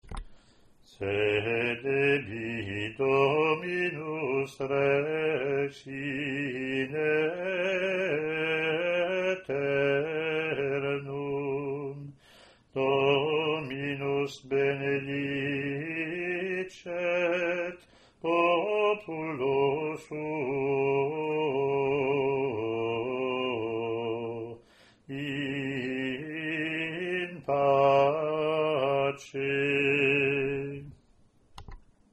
Latin antiphon + verse)